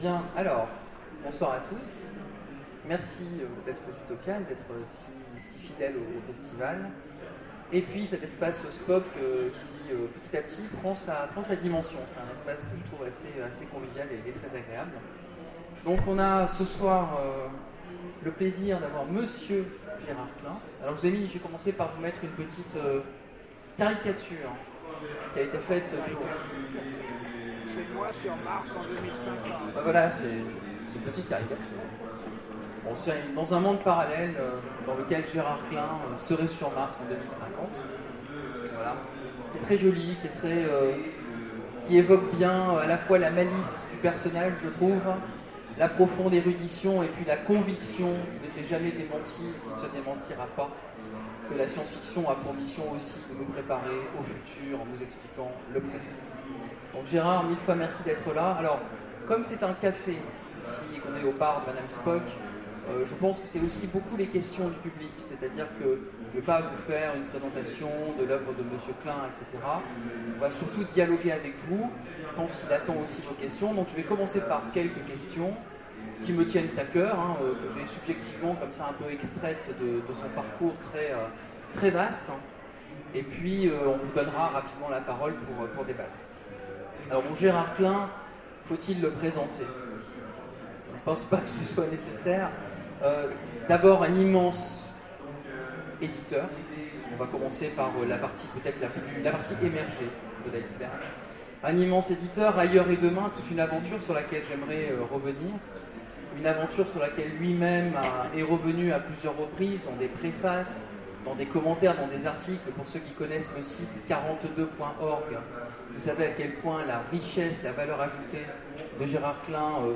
Utopiales 2014 : Rencontre avec Gérard Klein
- le 31/10/2017 Partager Commenter Utopiales 2014 : Rencontre avec Gérard Klein Télécharger le MP3 à lire aussi Gérard Klein Genres / Mots-clés Rencontre avec un auteur Conférence Partager cet article